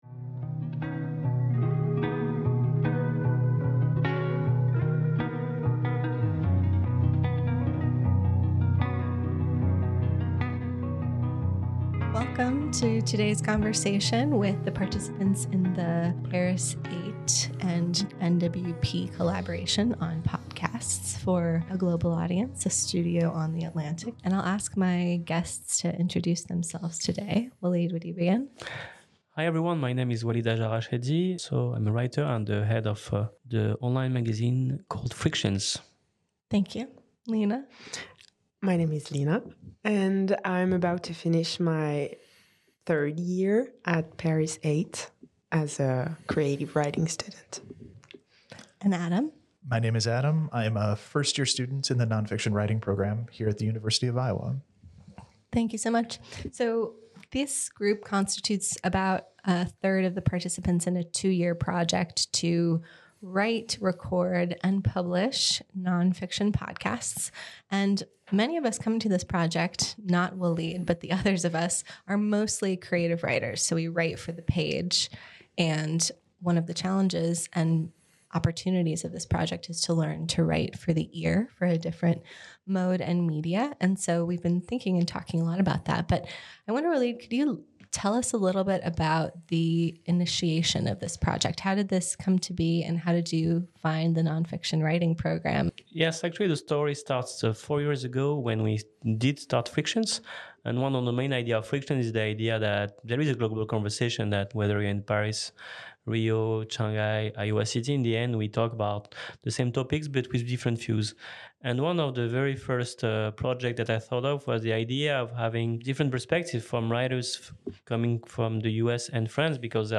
A Roundtable on Podcasting and Creative Collaboration